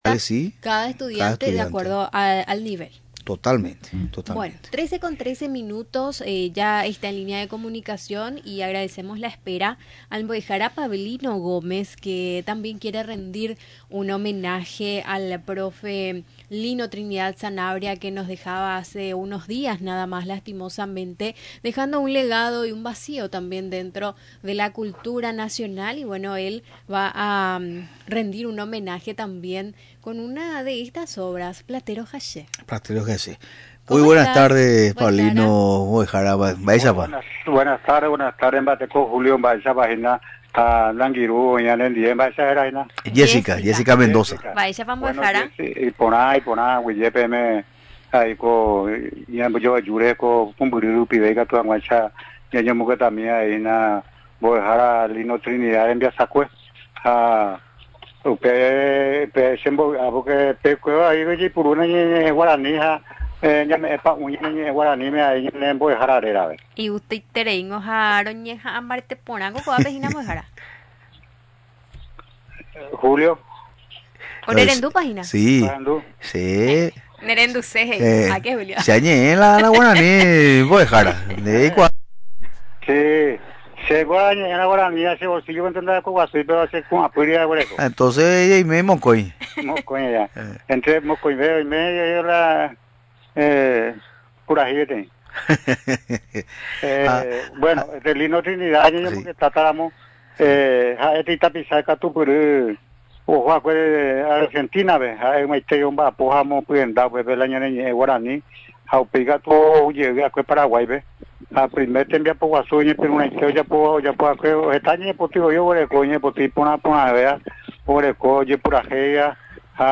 con la declamación del poema «Platero ha che».